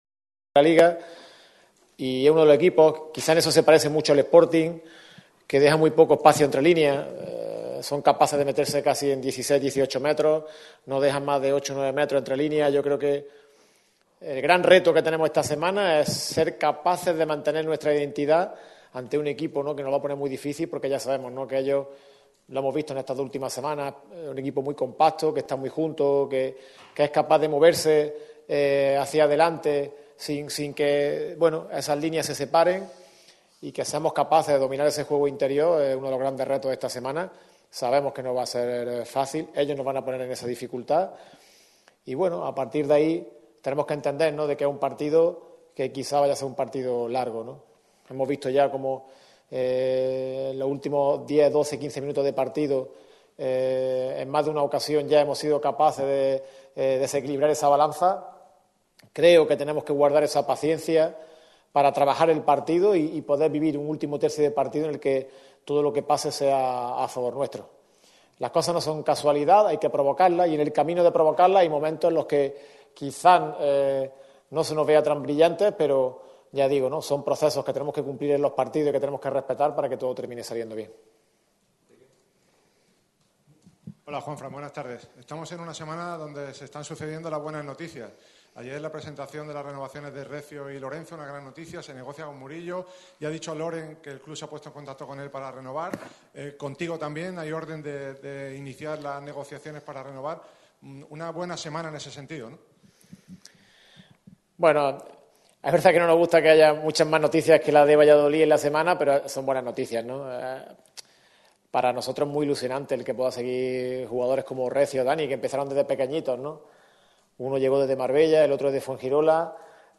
En la previa del partido ante el Real Valladolid, Juanfran Funes ha comparecido ante los medios para analizar el encuentro.